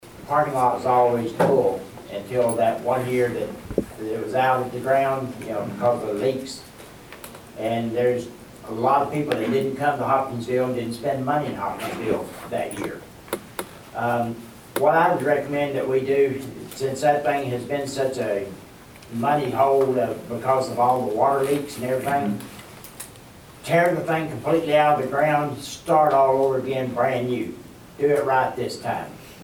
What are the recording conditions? At a town hall Wednesday night, the room may have been full of residents and city officials, but only a handful voiced opinions on Bluegrass Splash, and those were split.